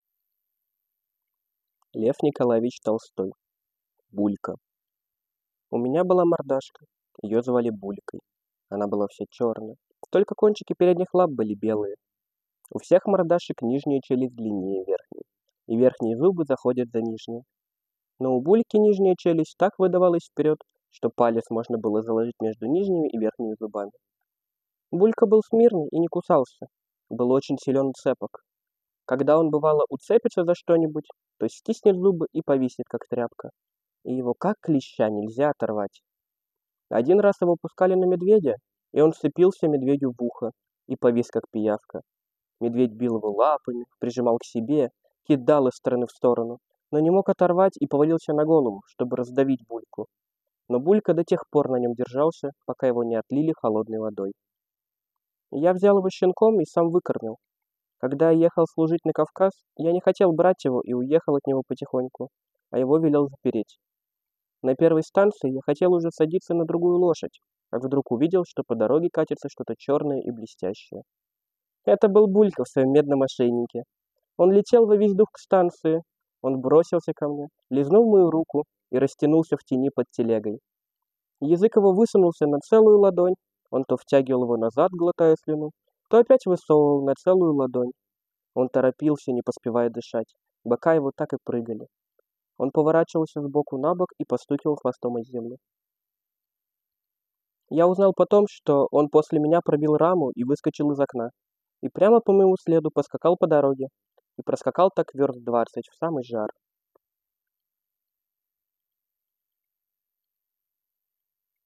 В честь этого дня в рамках проекта «Волонтёры читают» мы подготовили подарок для всех любителей животных и литературы — аудиорассказы Льва Николаевича Толстого «Котёнок» и «Булька»!